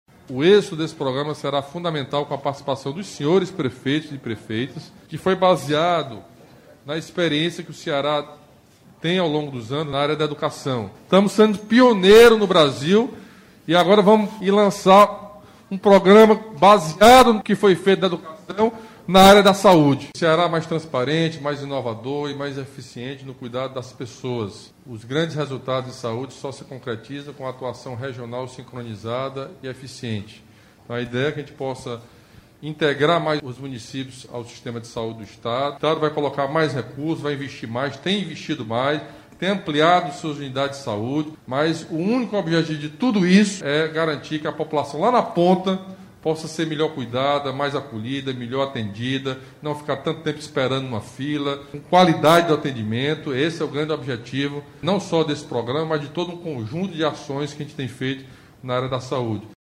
A pactuação de resultados entre os agentes públicos é um modelo tido como exitoso no Estado, como destacou o governador Camilo Santana.